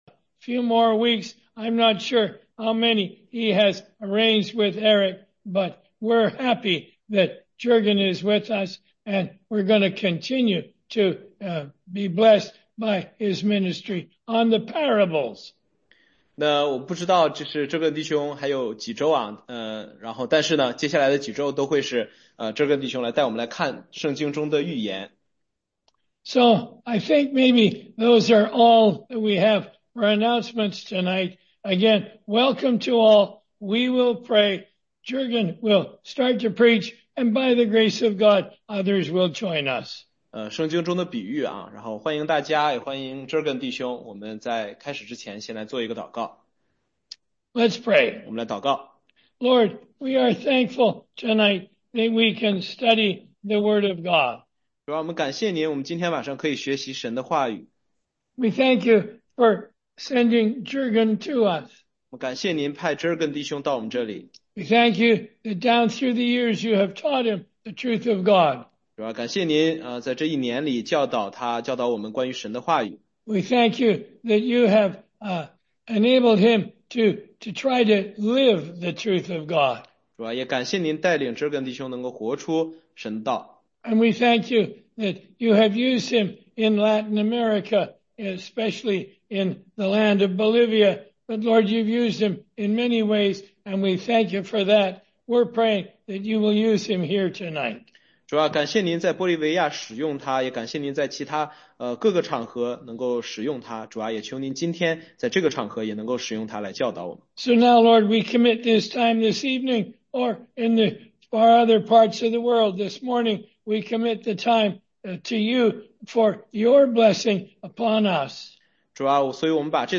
16街讲道录音
中英文查经